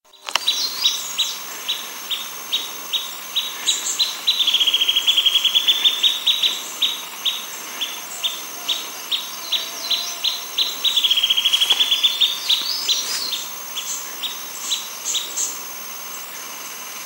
Rufous-breasted Leaftosser (Sclerurus scansor)
Life Stage: Adult
Location or protected area: Parque Provincial Cruce Caballero
Condition: Wild
Certainty: Recorded vocal